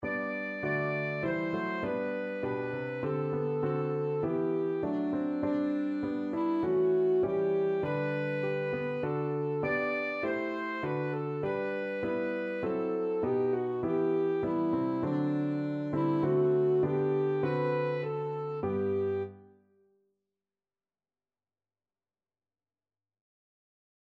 Christian Christian Alto Saxophone Sheet Music The King of Love My Shepherd Is
Alto Saxophone
G major (Sounding Pitch) E major (Alto Saxophone in Eb) (View more G major Music for Saxophone )
4/4 (View more 4/4 Music)
Traditional (View more Traditional Saxophone Music)